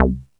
FUNBASS1.wav